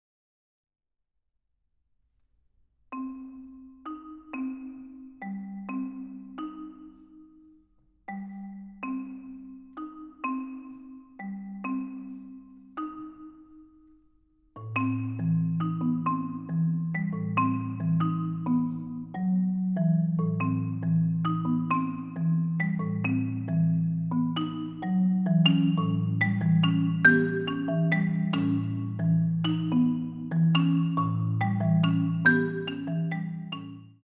This CD contains six world premier works for Marimba.
for solo marimba